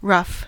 Ääntäminen
IPA : /ɹʌf/